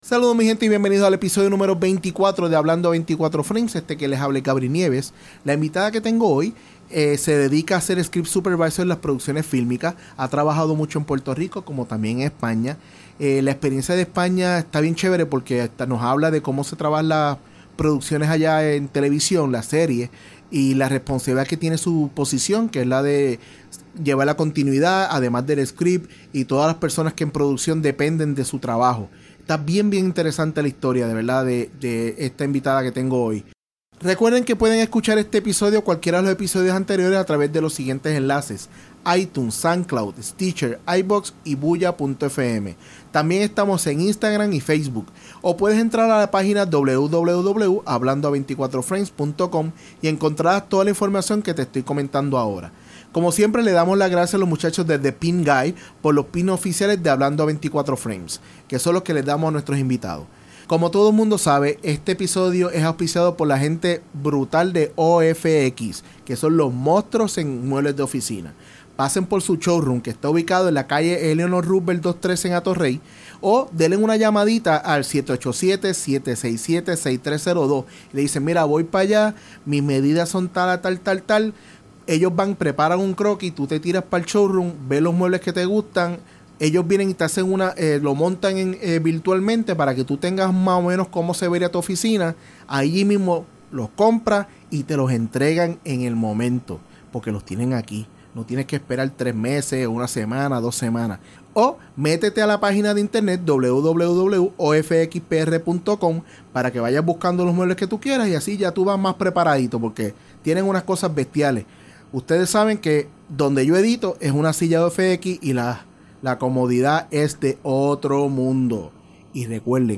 En el episodio #24 hablo con una profesional del campo, ella se especializa como script supervisor o como se le conoce en España script ya que ella a trabajado mucho en nuestra isla pero su actual desarrollo profesional es en España.